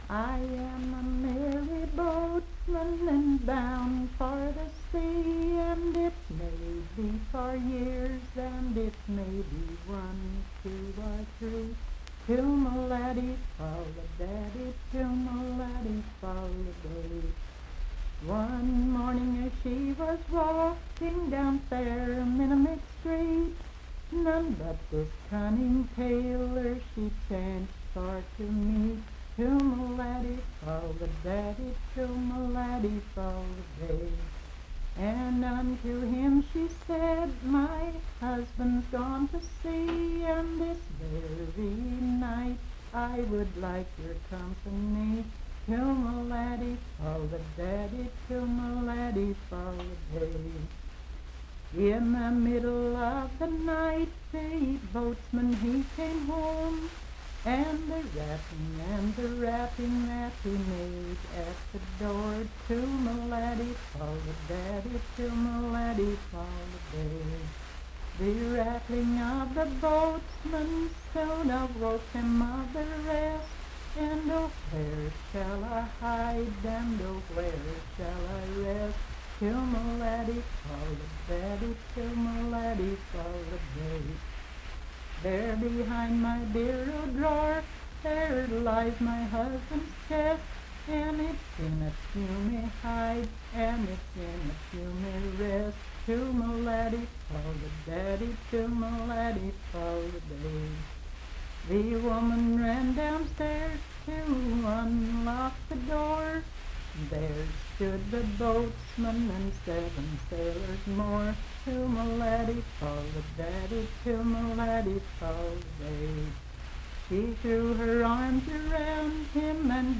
Unaccompanied vocal music
Verse-refrain 12d(3w/R).
Voice (sung)